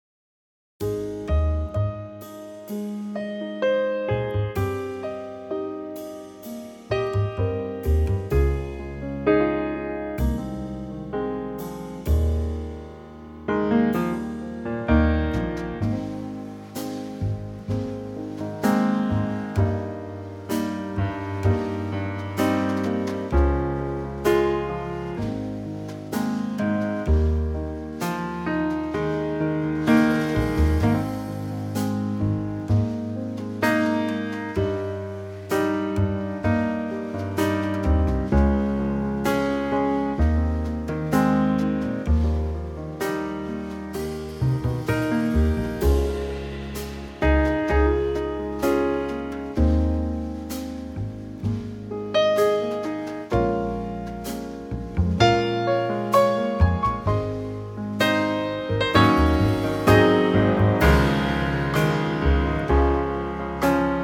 Unique Backing Tracks
4 bar intro and vocal in at 15 seconds. vocal through
key - Ab - vocal range - G to Db
very lovely Trio arrangement